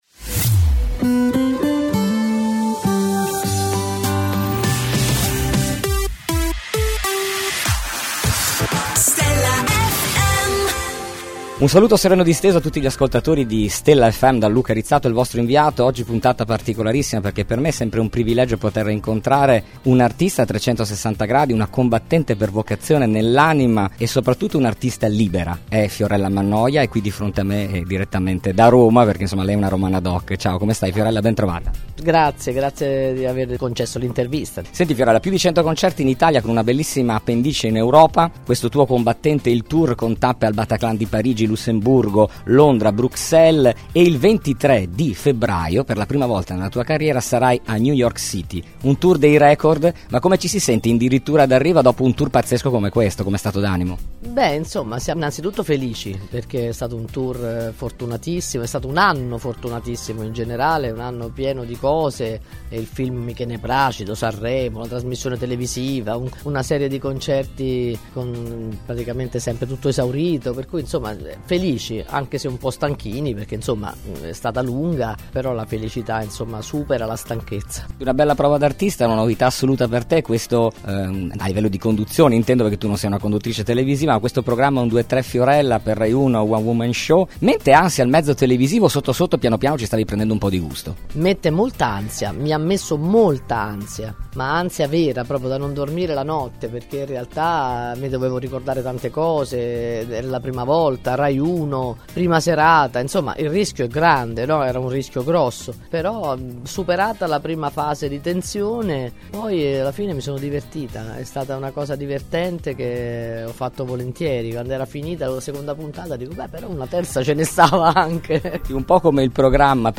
Intervista esclusiva dell’inviato per Stella Fm a Fiorella Mannoia.